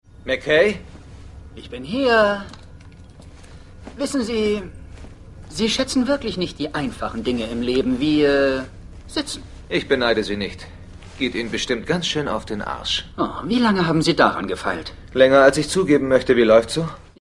Sheppard und McKay unterhalten sich in Rodneys Labor.